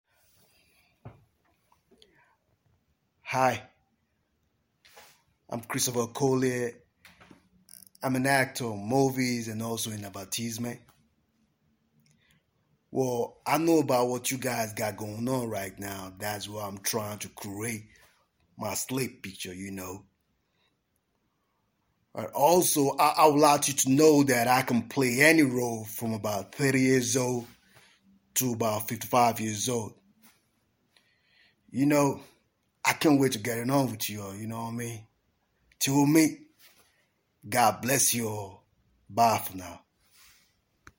Language Reels
English-American level: native